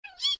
AV_rabbit_exclaim.ogg